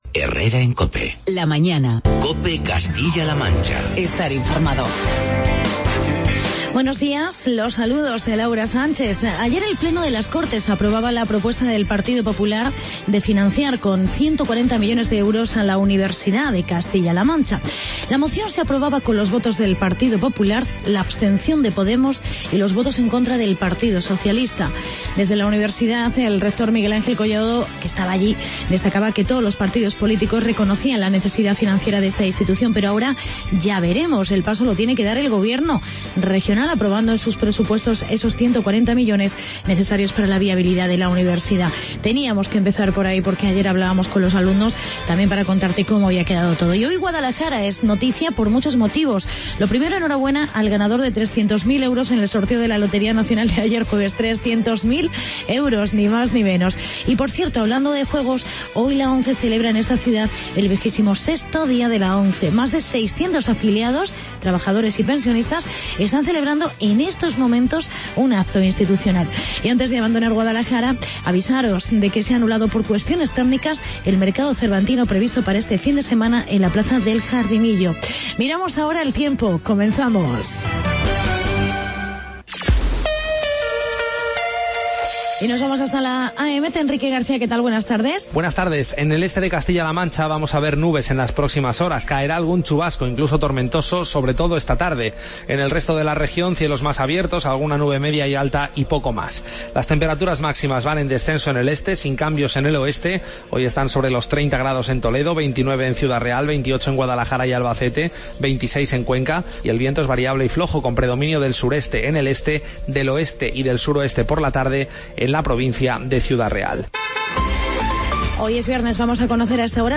Actualidad regional y entrevista